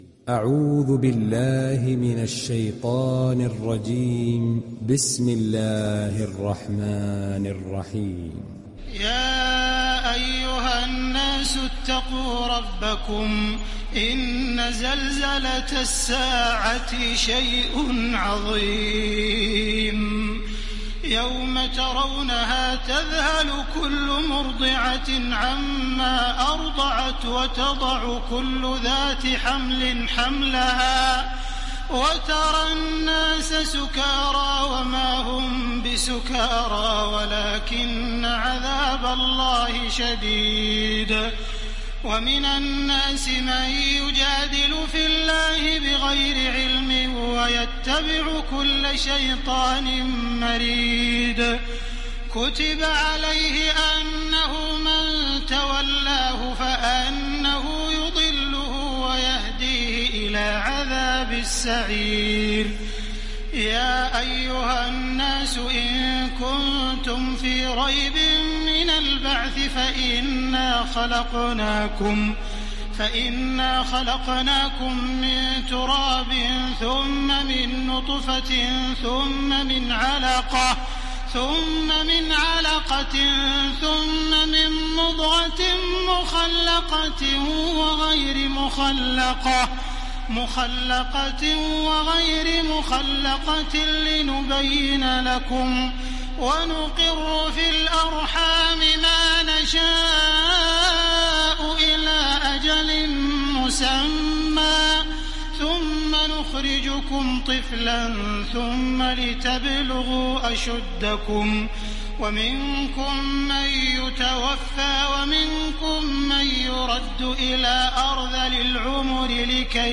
دانلود سوره الحج mp3 تراويح الحرم المكي 1430 روایت حفص از عاصم, قرآن را دانلود کنید و گوش کن mp3 ، لینک مستقیم کامل
دانلود سوره الحج تراويح الحرم المكي 1430